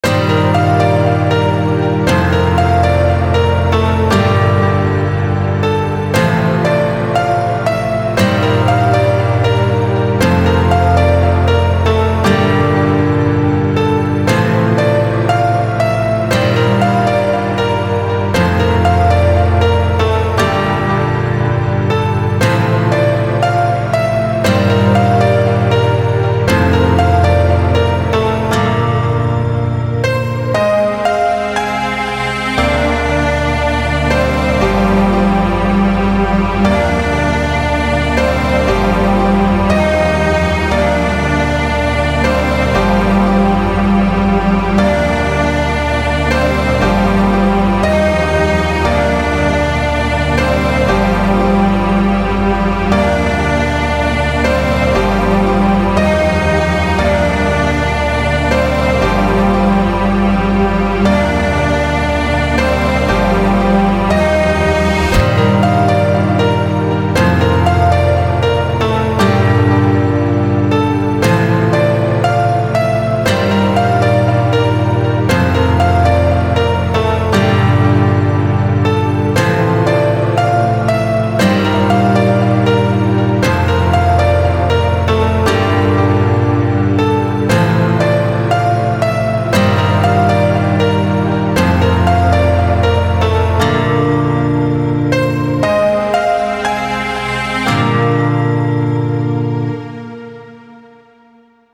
• Music has an ending (Doesn't loop)